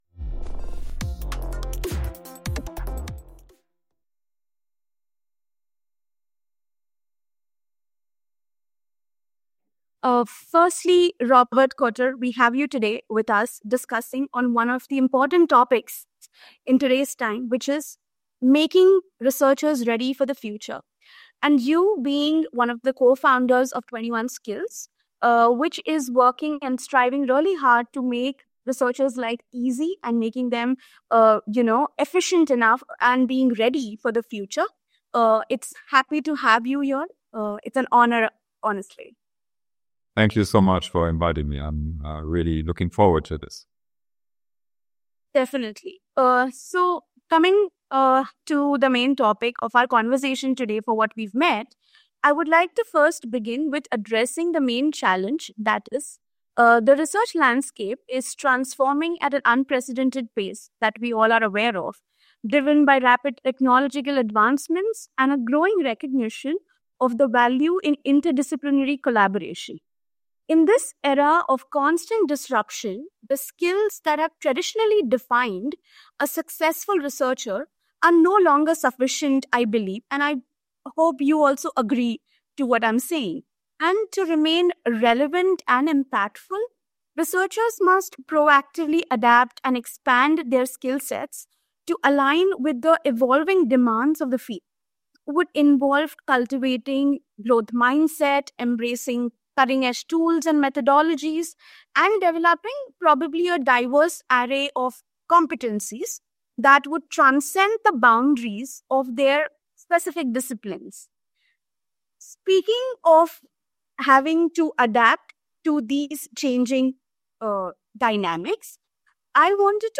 Tune in for an insightful discussion with actionable insights that encourages a mindset of continuous learning and adaptation in research.